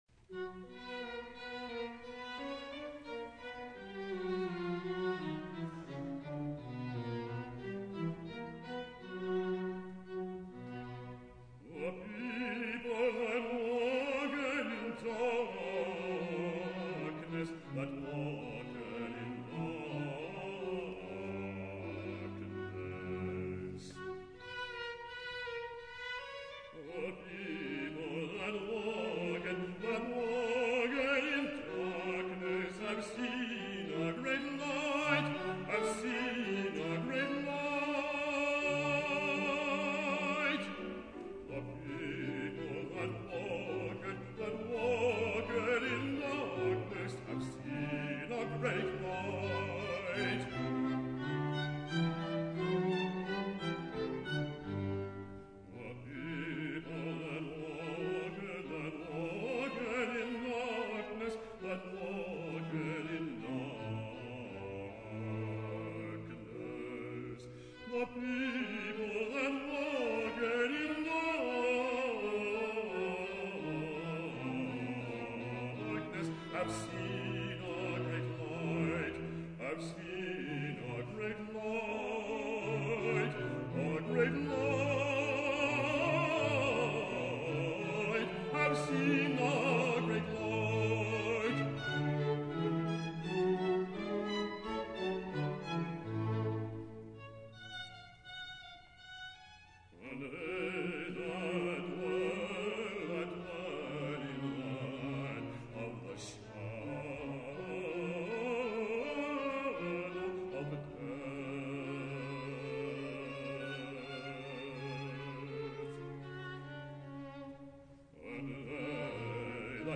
Opera Demos